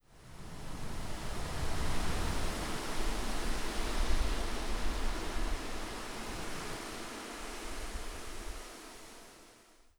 Gust 3.wav